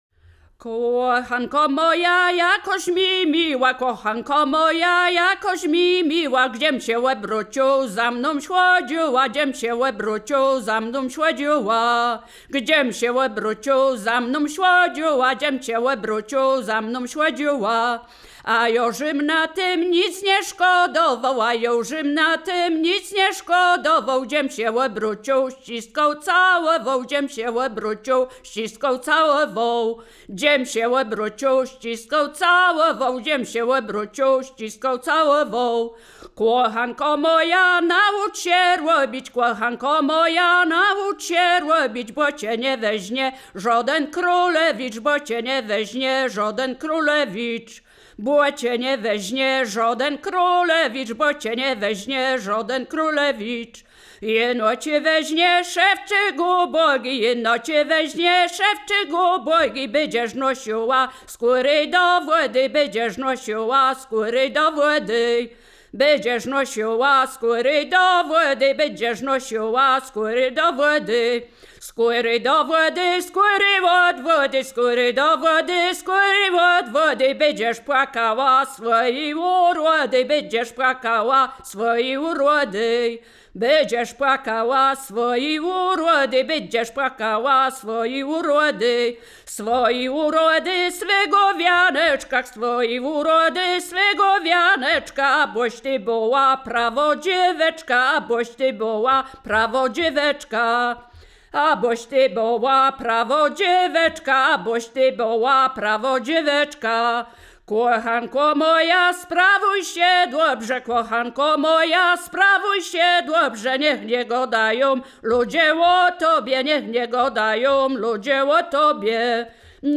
województwo wielkopolskie, powiat gostyński, gmina Krobia, wieś Posadowo
żartobliwe przyśpiewki miłosne